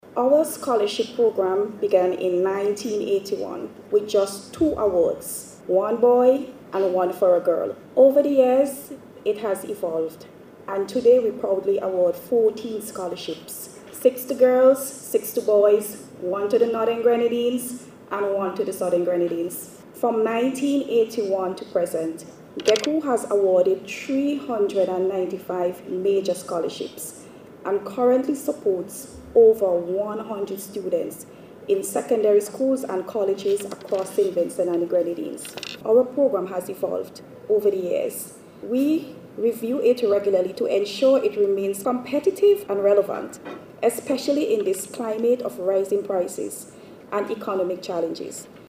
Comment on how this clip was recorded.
On Tuesday, GECCU awarded scholarships to fourteen students at its 23rd Annual Scholarship Awards Ceremony at the Methodist Church Hall.